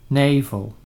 Nivelles (French: [nivɛl] ; Dutch: Nijvel [ˈnɛivəl]
Nl-Nijvel.ogg.mp3